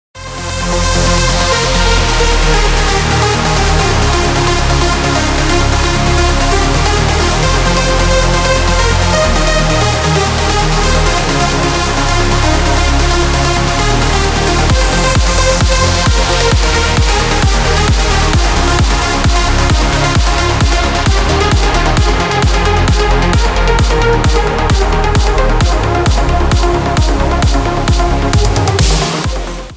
• Качество: 320, Stereo
dance
Electronic
без слов
club
Жанр: Trance / Progressive House